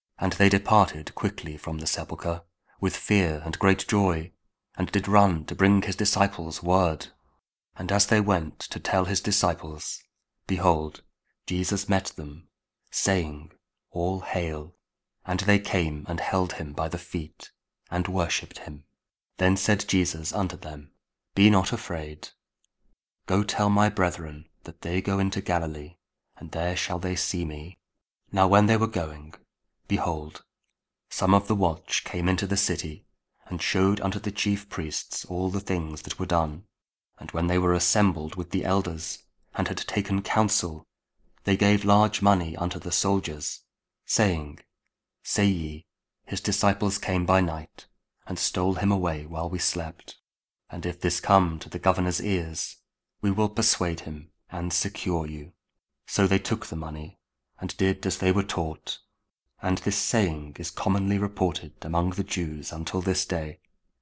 The Gospel Of Saint Matthew 28: 8-15 | King James Audio Bible | KJV